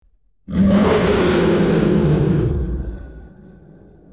Tiger Roar Strong Hd Téléchargement d'Effet Sonore
Tiger Roar Strong Hd Bouton sonore